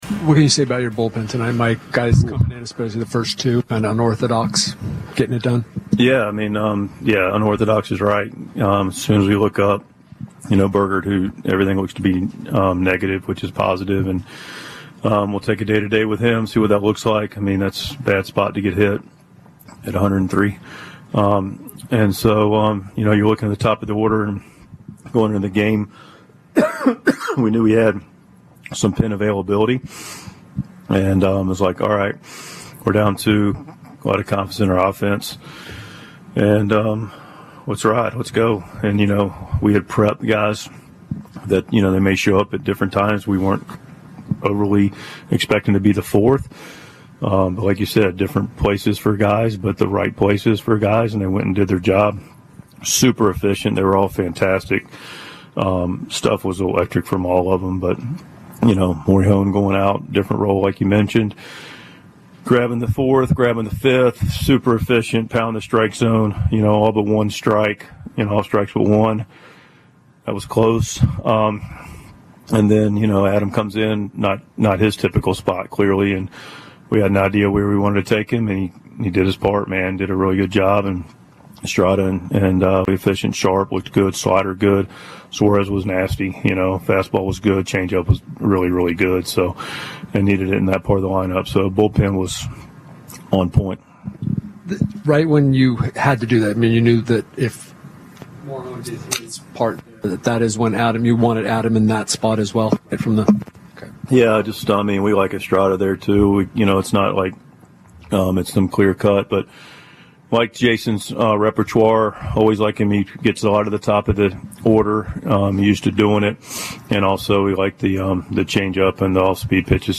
Mike Shildt's postgame reaction after Tuesday's 4-3 win over the Nationals.